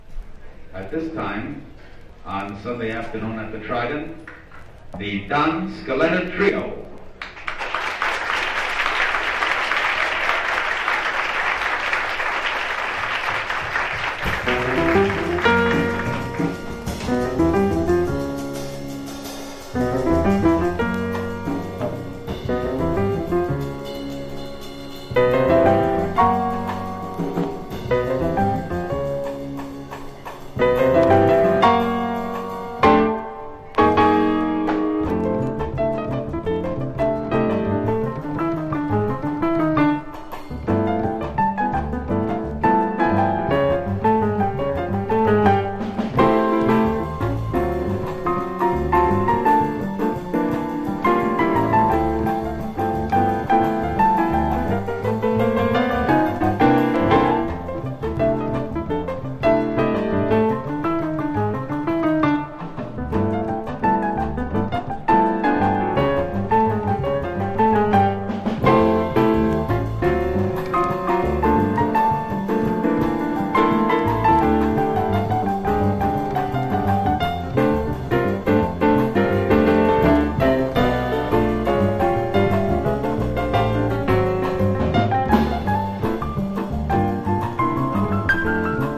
ハードバップ# MODAL